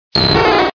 Cri de Minidraco dans Pokémon Diamant et Perle.